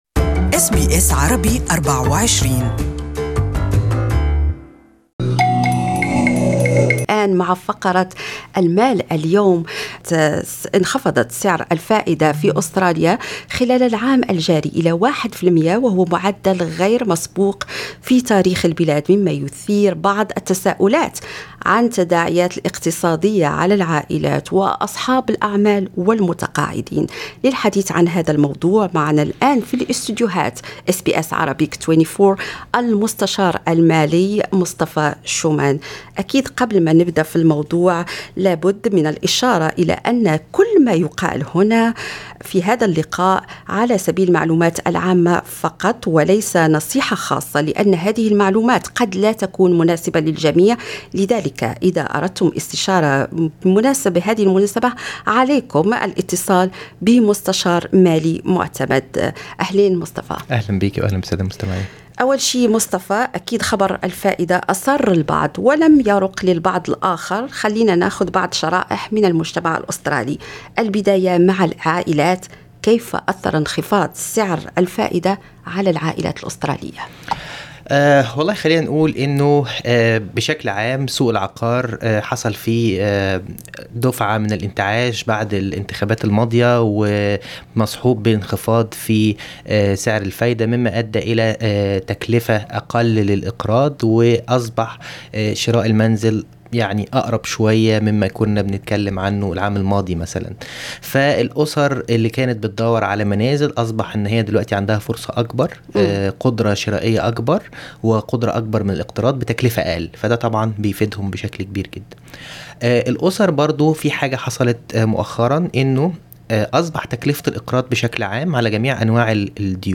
لا بدّ من الإشارة إلى أن كل ما يُقال في هذا اللقاء هو على سبيل المعلومات العامة فقط، وليس نصيحة خاصة، لأن هذه المعلومات قد لا تكون مناسبة لكم.